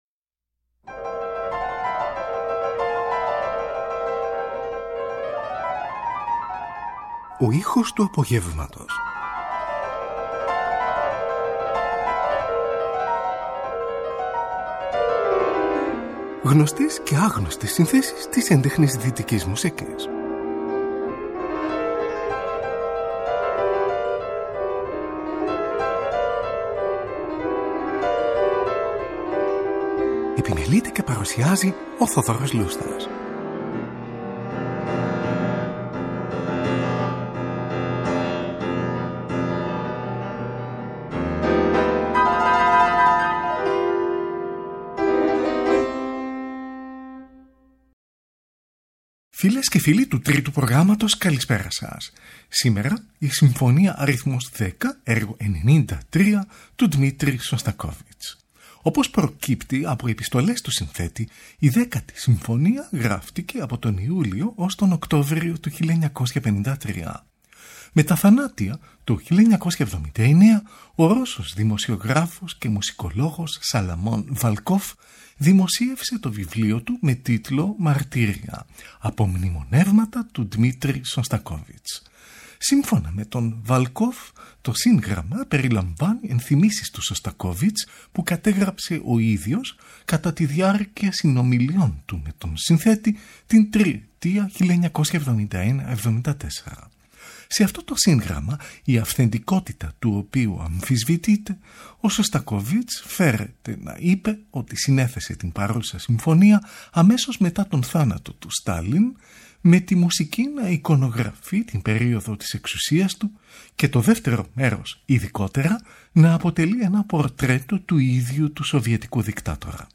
Βασικό μελωδικό θέμα της κινηματογραφικής μουσικής
πιάνο